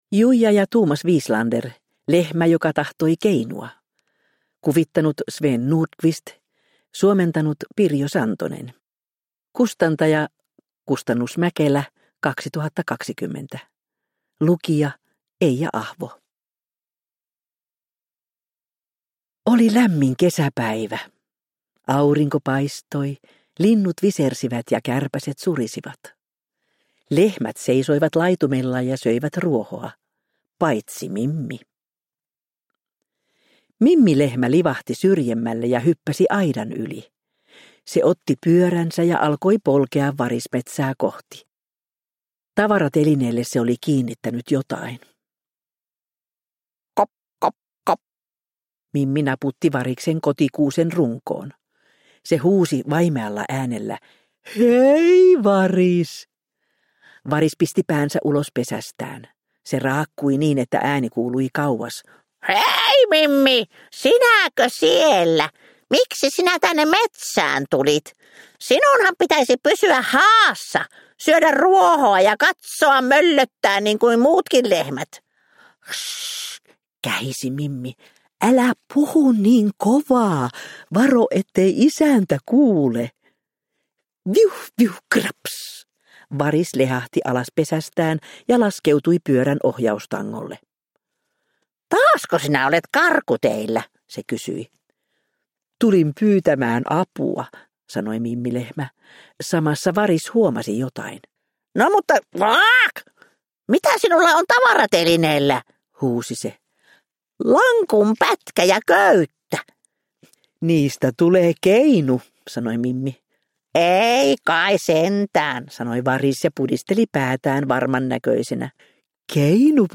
Lehmä, joka tahtoi keinua – Ljudbok – Laddas ner